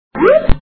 8_jump2.mp3